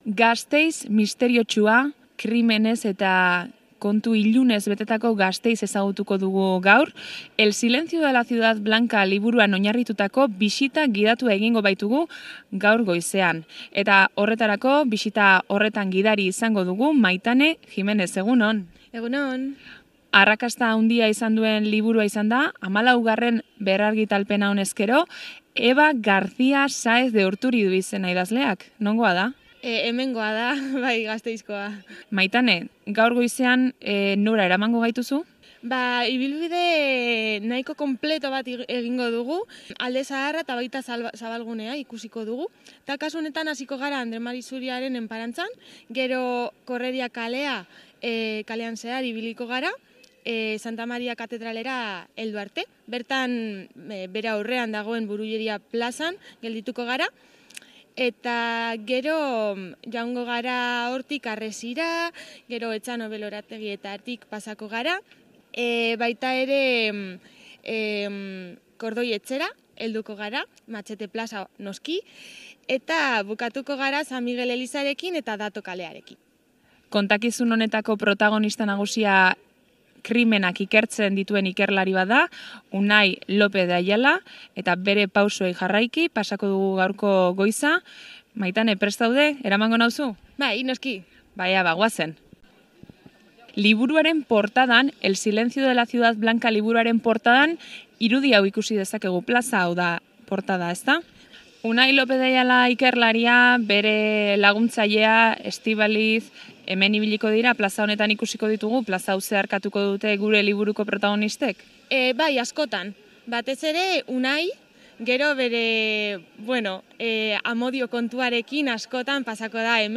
El Silencio de la Ciudad Blanca liburuan oinarritutako bisita gidatua egin dugu Artea bisita gidatuen eskutik.